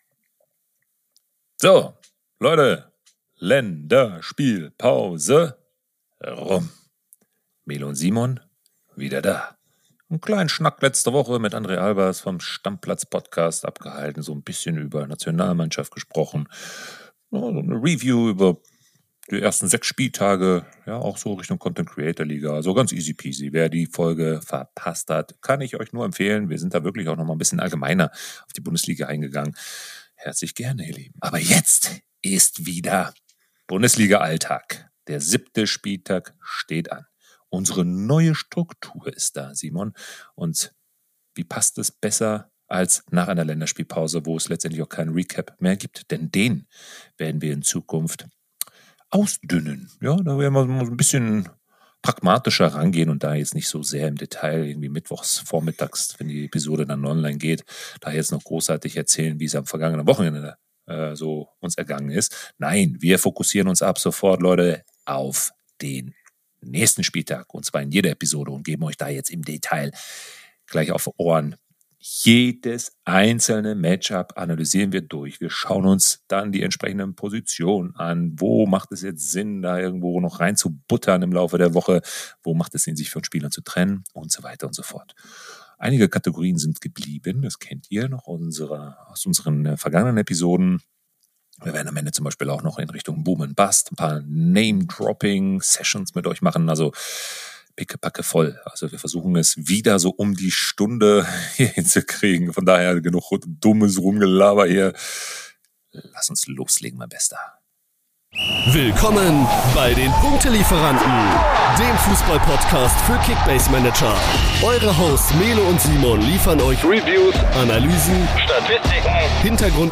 Unsere Hosts: Erlebe die perfekte Mischung aus Humor und Analyse!